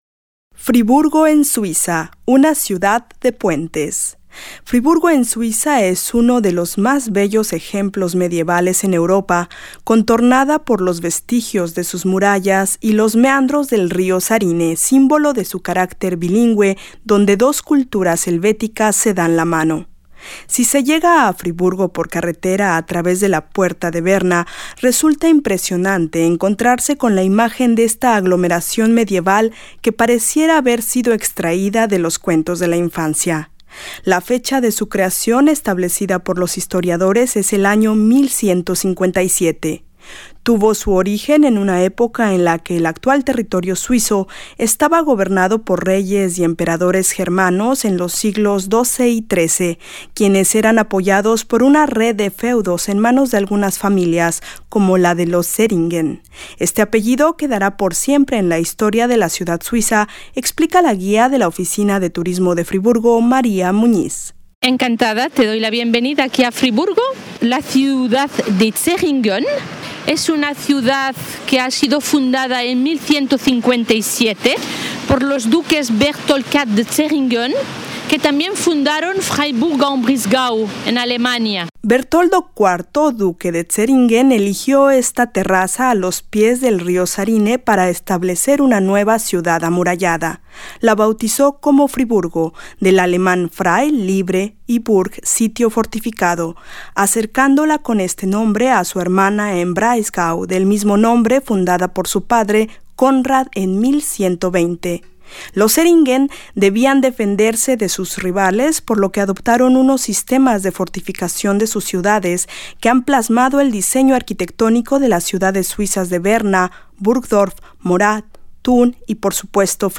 Uno de los más bellos ejemplos medievales en Europa, esta ciudad contornada por los vestigios de sus murallas y los meandros del río Sarine, símbolo de su carácter bilingüe, que permite ser puente de dos culturas. Reportaje